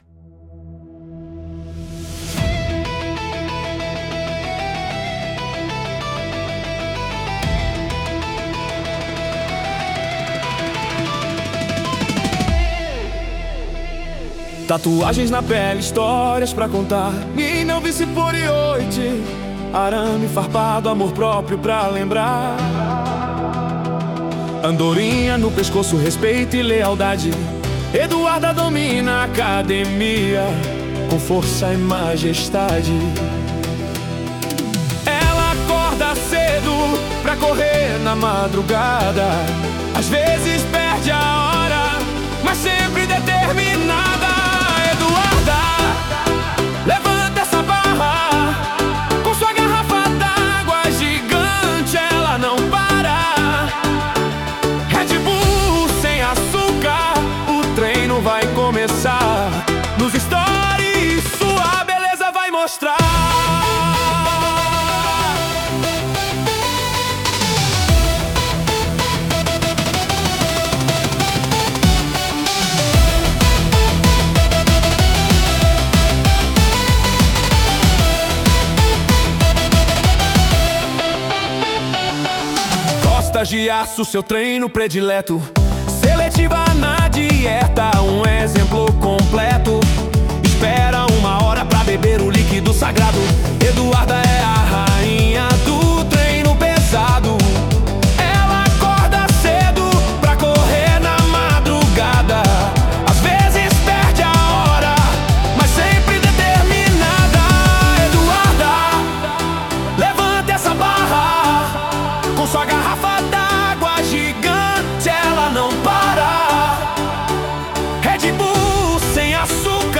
Versão Technobrega 1